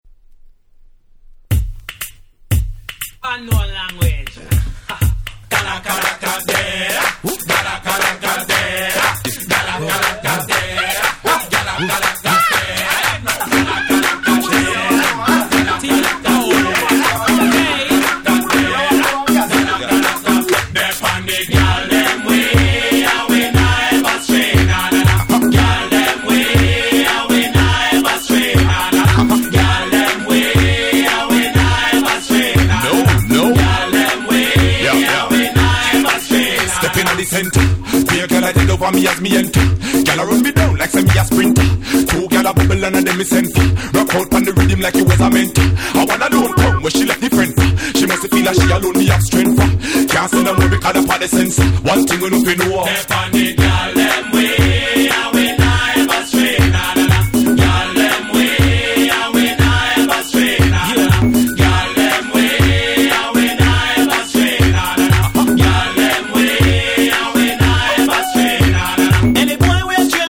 03' Big Hit Dancehall Reggae !!